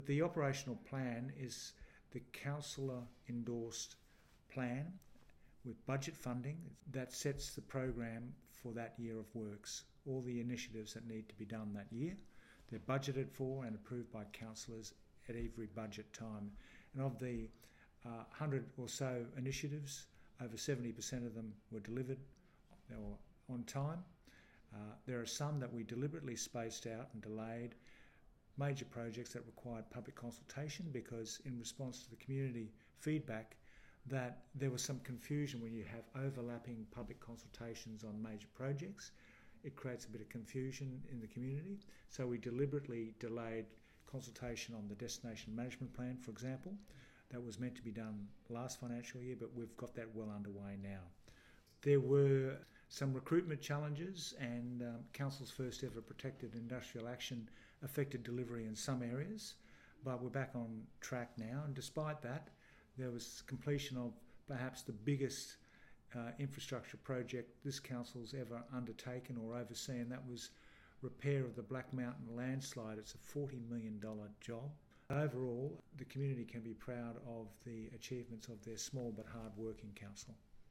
Mayor Frank Wilkie discusses Noosa Council's progress in delivering its 2024/25 Operational Plan: